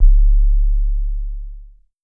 LOW BASS 2.wav